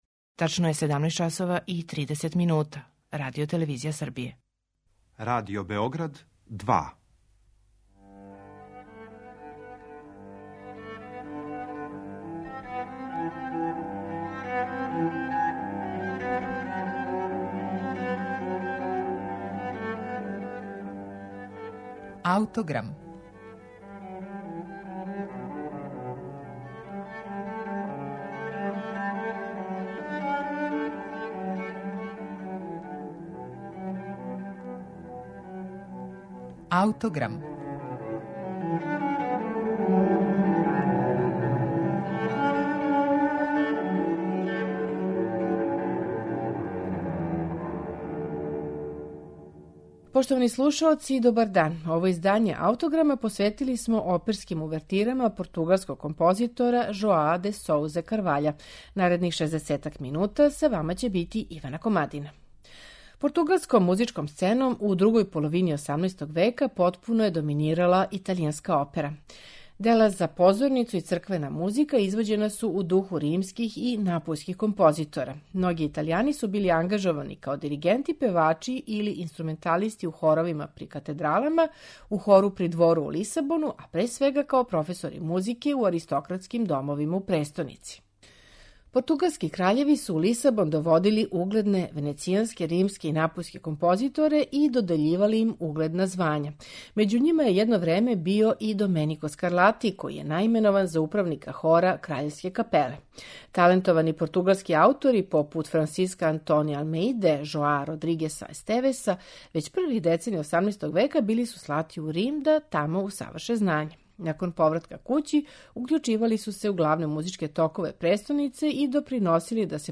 Слушаћете их у интерпретацији камерног оркестра Франц Лист , под управом Јаноша Роле.